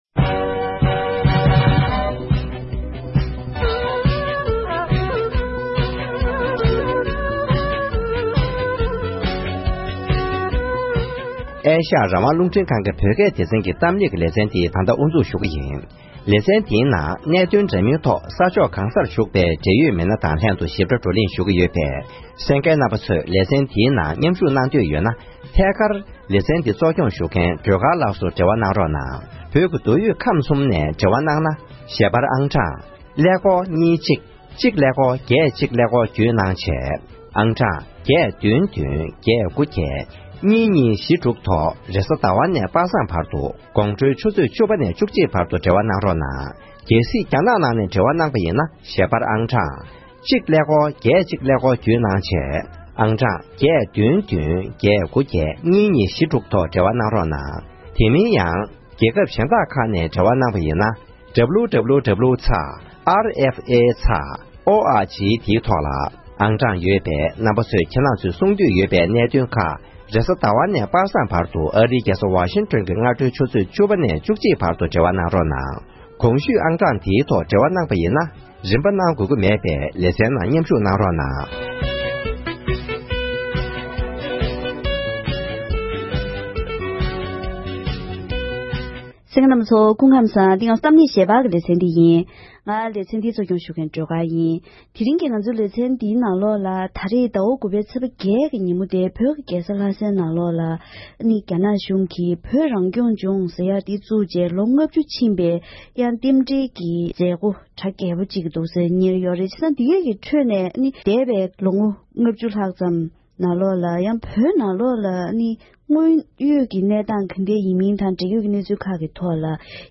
དངོས་ཡོད་བོད་ནང་གནས་སྟངས་ཇི་འདྲ་ཡོད་མེད་སྐོར་འབྲེལ་ཡོད་དང་ལྷན་བཀའ་མོལ་ཞུས་པ་ཞིག་གསན་རོགས་གནང་།